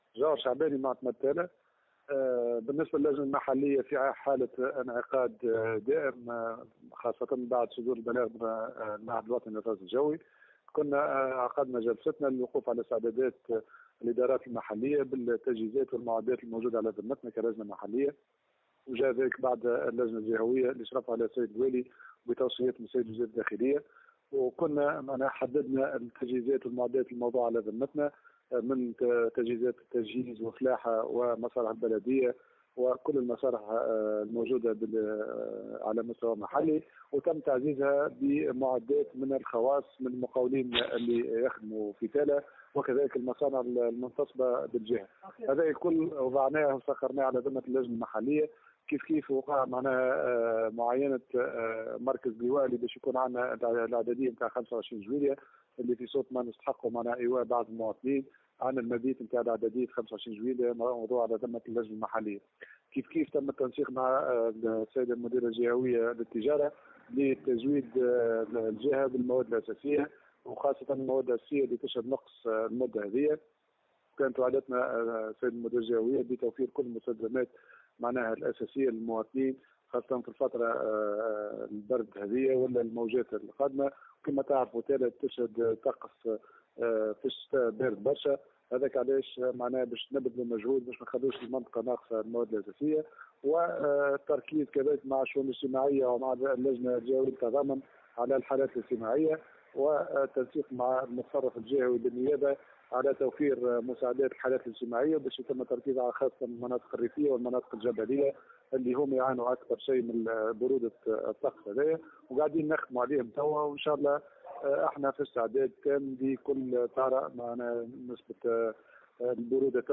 مزيد التفاصيل في التصريح التالي لجوهر شعباني معتمد تالة :